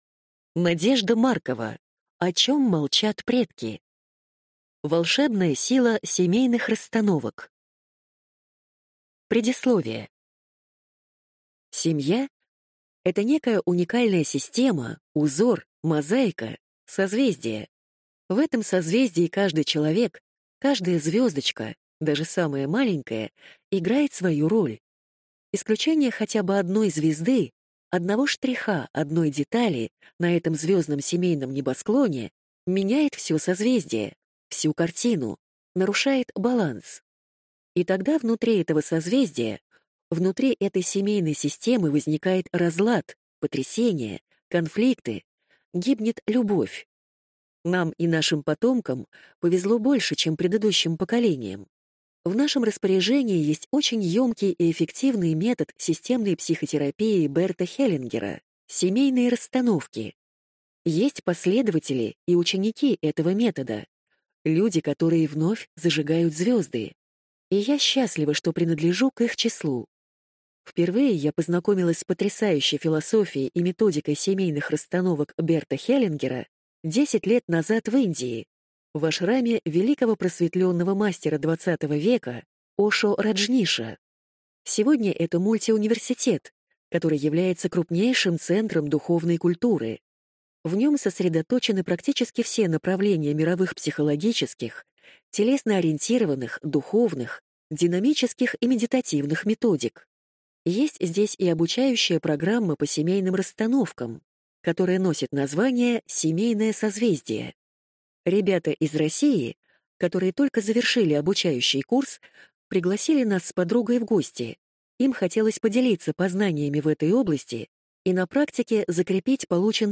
Аудиокнига О чем молчат предки | Библиотека аудиокниг